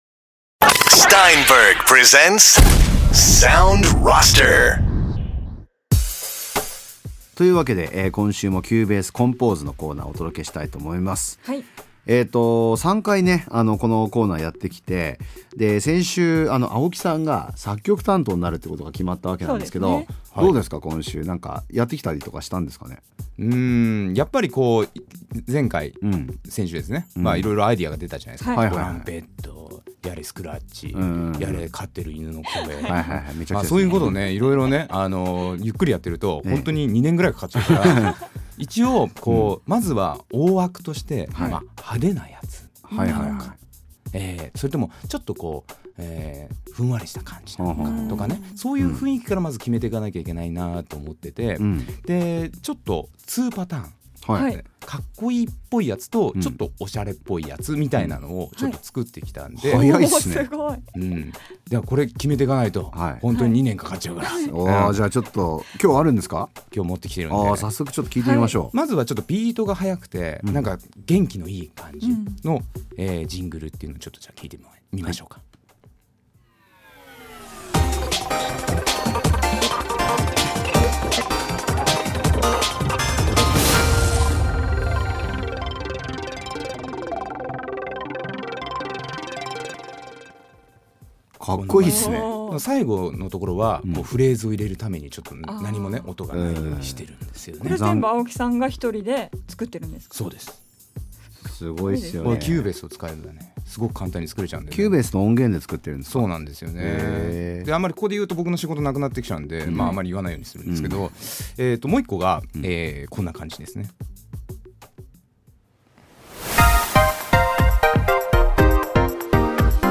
Steinberg が提供するラジオ番組「Sound Roster」。